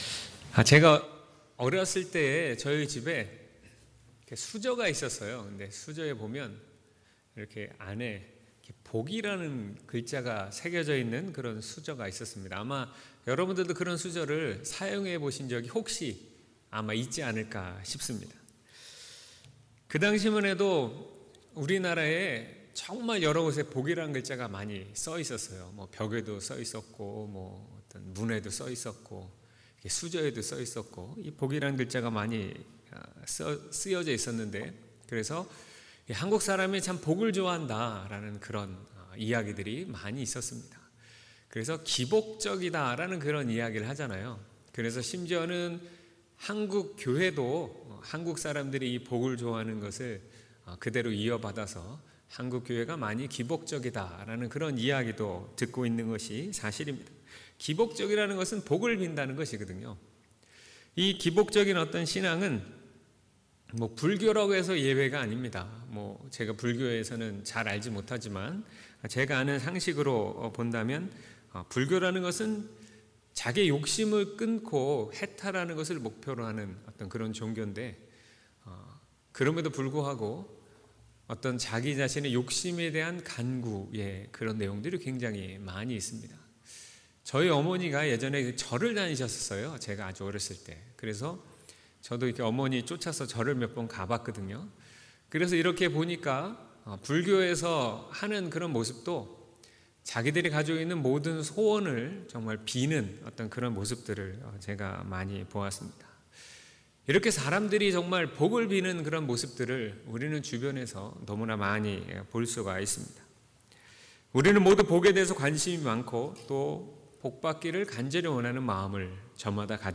2019년 4월 28일 주일 설교 / 복 있는 사람/ 시1:1-6